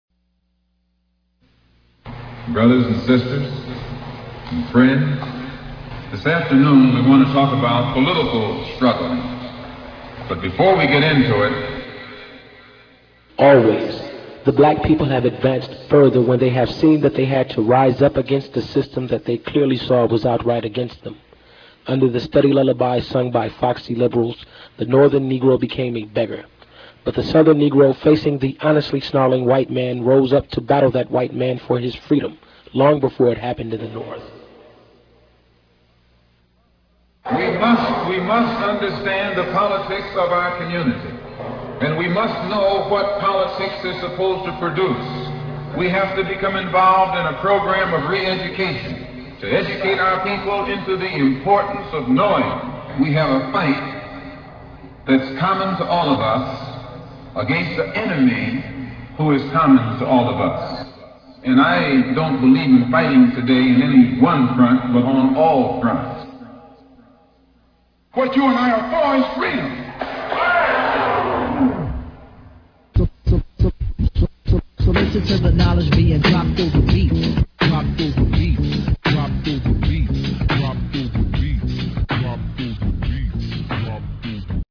Taken from the speech "The Ballot or the Bullet" by Malcolm X on First Amendment Records (recorded live in Detroit, MI on April 12, 1964)